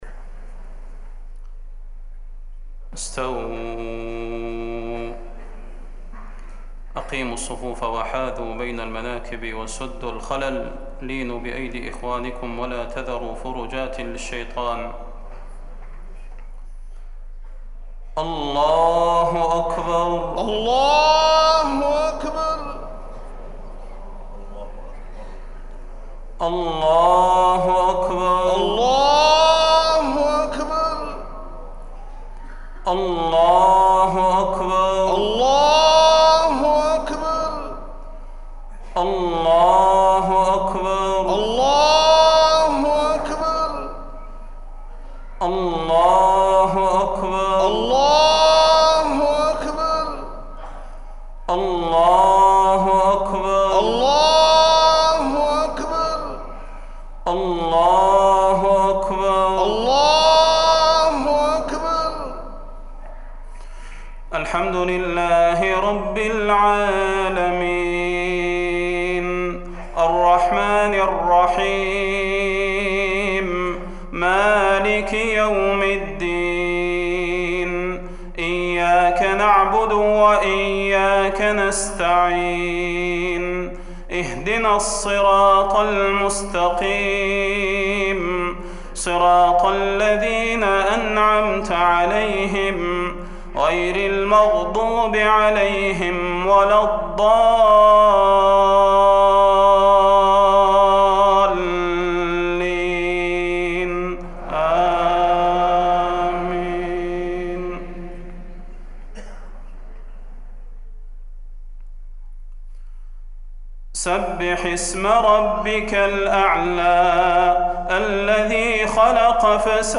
خطبة عيد الأضحى - المدينة - الشيخ صلاح البدير1426
تاريخ النشر ١٠ ذو الحجة ١٤٢٦ هـ المكان: المسجد النبوي الشيخ: فضيلة الشيخ د. صلاح بن محمد البدير فضيلة الشيخ د. صلاح بن محمد البدير خطبة عيد الأضحى - المدينة - الشيخ صلاح البدير1426